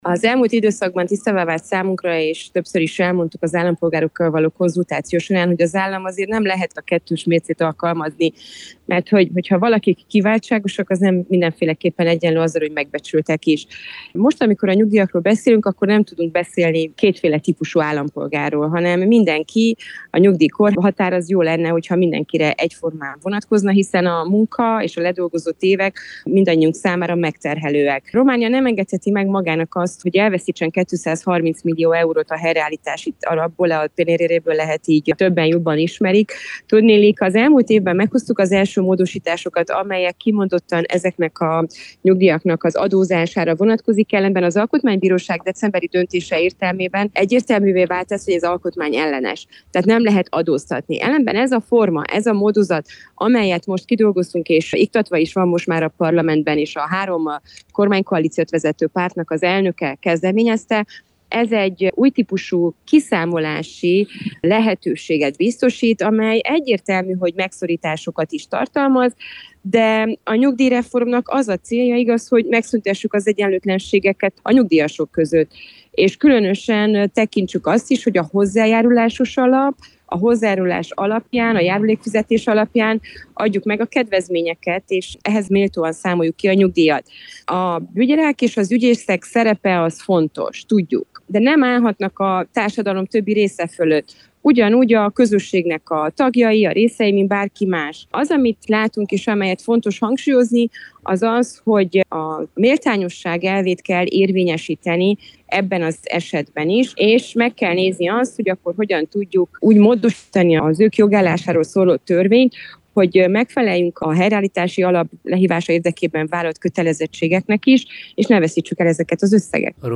A különleges nyugdíjak csökkentését is tartalmazó tervezetről Csép Éva Andrea képviselővel beszélgettünk.